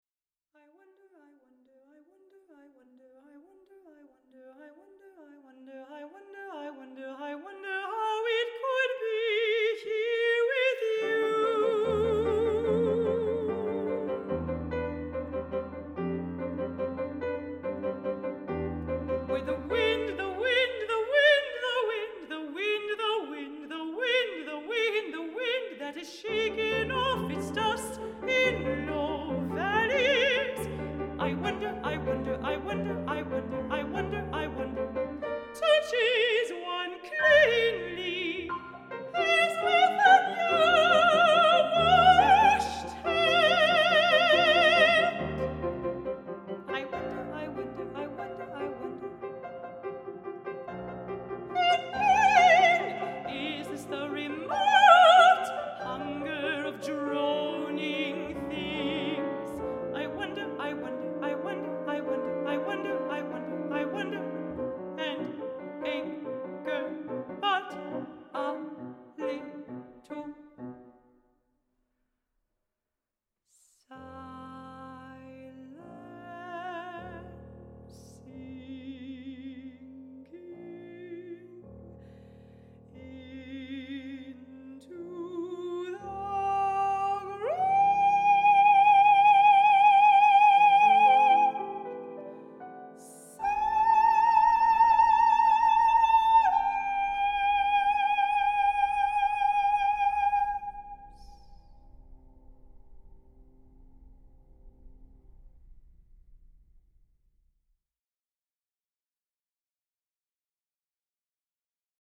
mezzo-soprano
piano
mezzo-soprano and piano